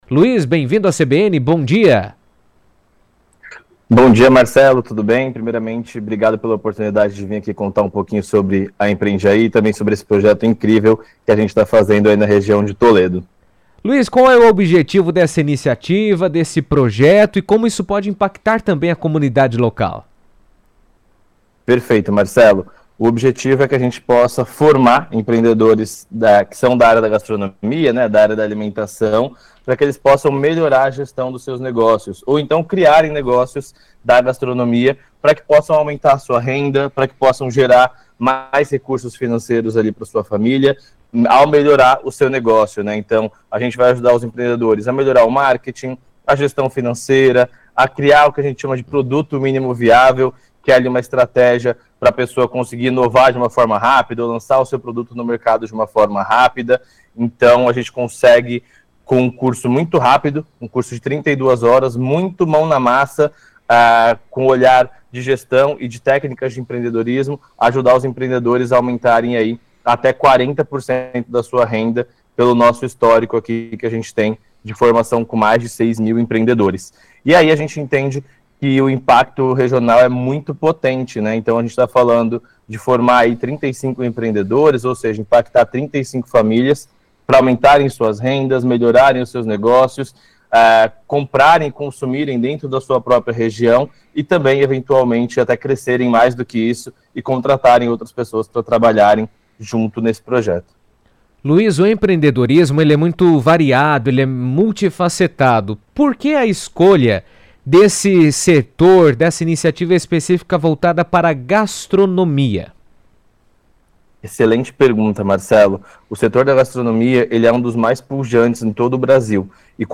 comentou sobre o projeto em entrevista à CBN